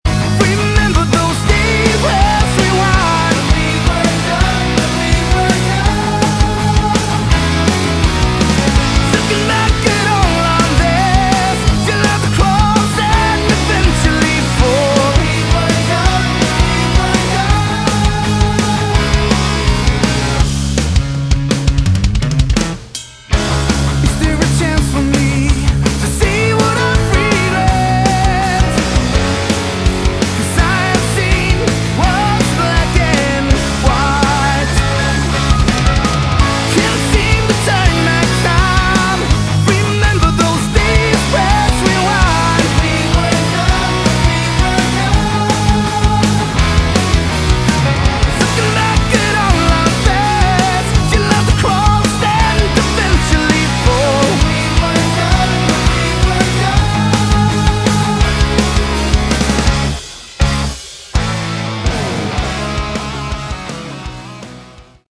Category: Modern AOR
Lead Vocals
Keyboard, Backing Vocals
Guitar, Backing Vocals
Bass, Backing Vocals
Drums, Percussion
Cello
Backing Vocals